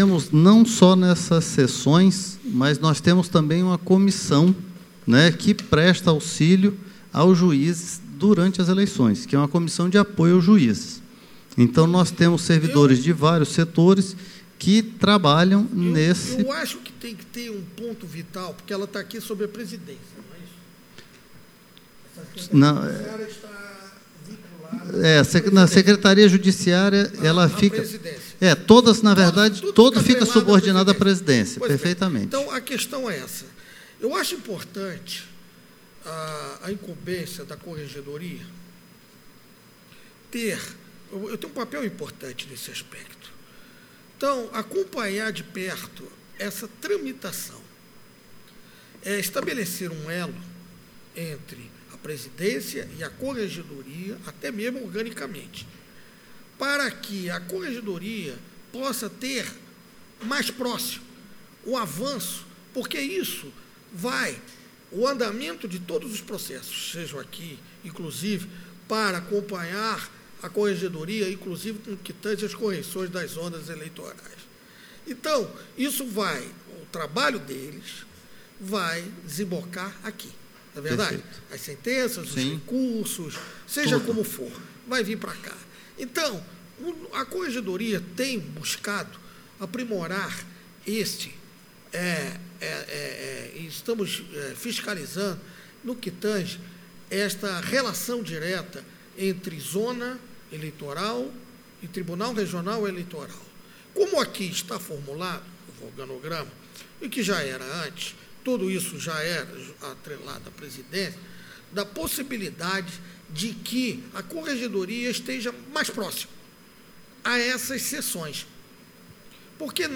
Áudio da 81.ª SESSÃO ORDINÁRIA DE 11 DE NOVEMBRO DE 2019 parte 02